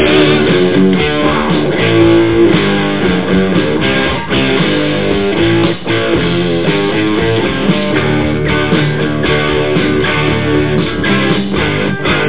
Amiga 8-bit Sampled Voice
1 channel
imhere.mp3